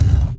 AsteroidHit.mp3